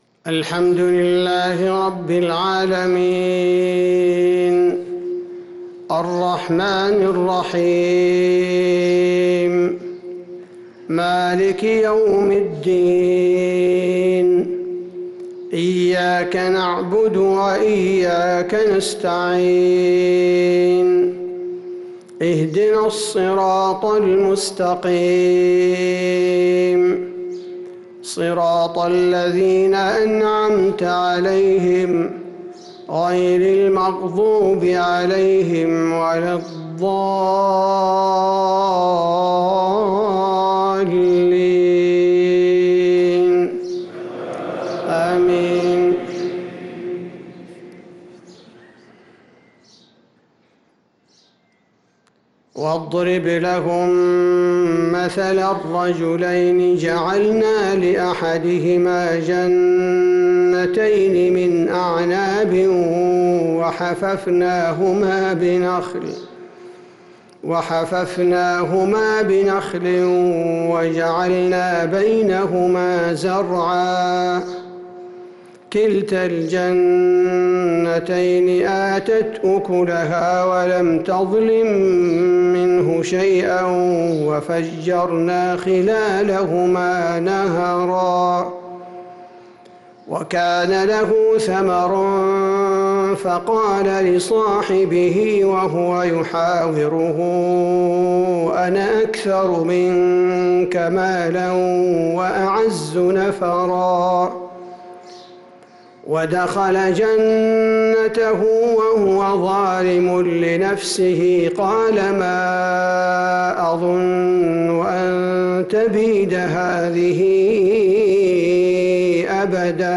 فجر الإثنين 3-9-1446هـ من سورة الكهف 32-49 | Fajr prayer from Surat al-Kahf 3-3-2025 > 1446 🕌 > الفروض - تلاوات الحرمين